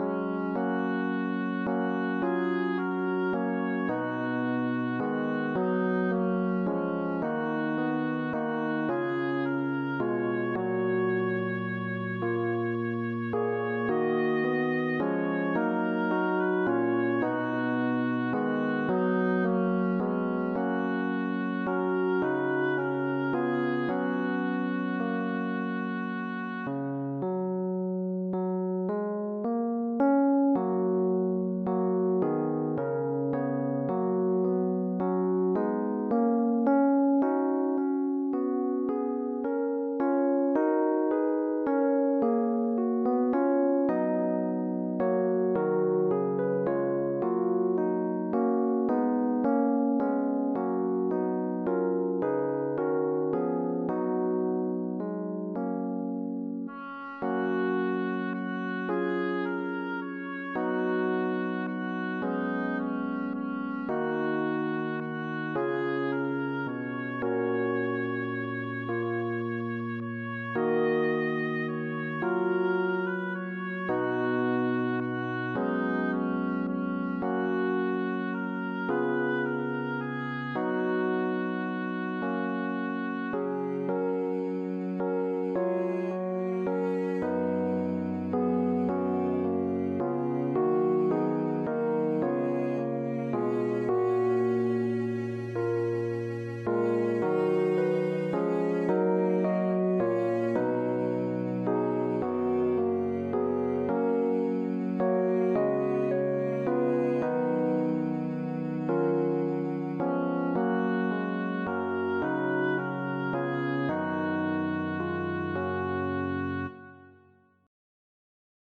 Number of voices: 5vv Voicing: SATBB Genre: Sacred, Anthem
Language: English Instruments: A cappella